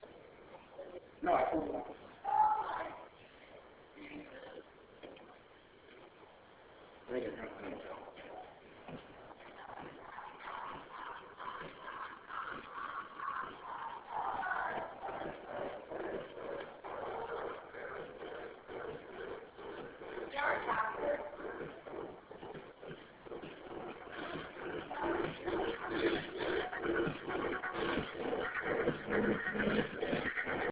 Field Recording 2
Hallway in the Netherlands 9:00 People talking
someone else asking for the bathroom, and finally louder music